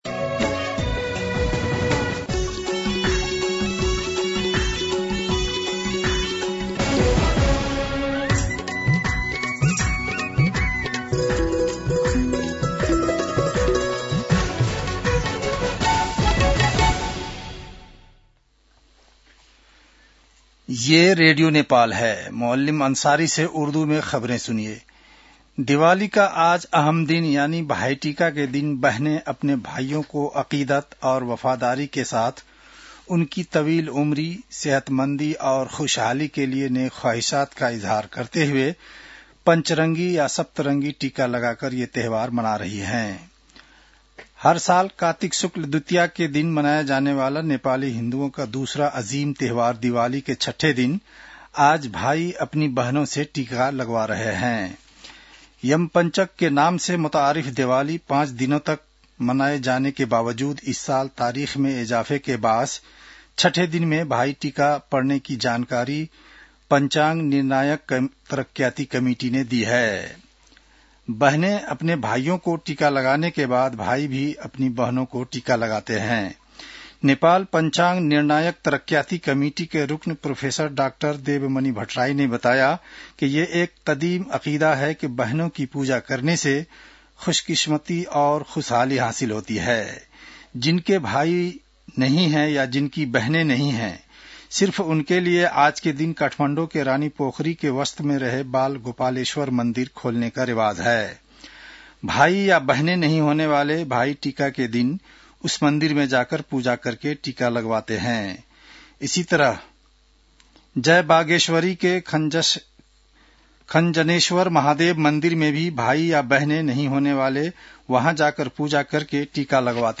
उर्दु भाषामा समाचार : ६ कार्तिक , २०८२